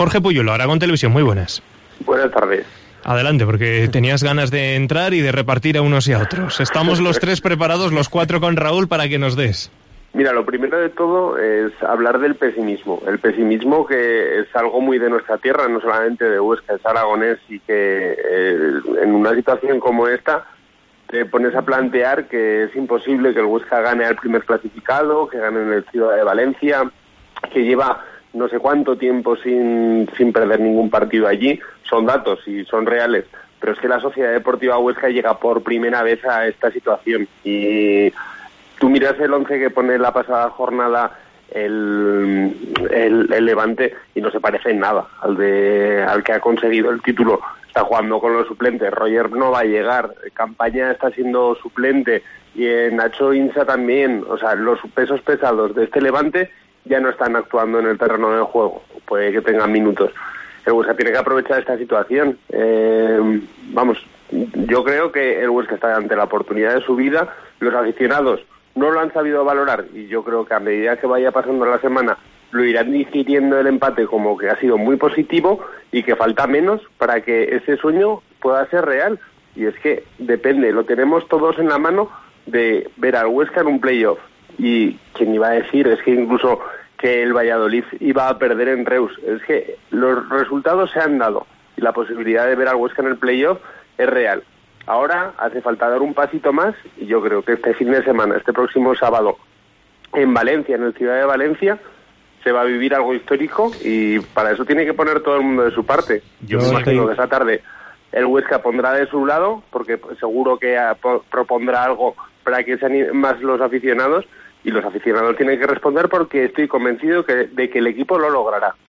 participa en la tertulia de los lunes dando su opinión sobre el momento actual del Huesca.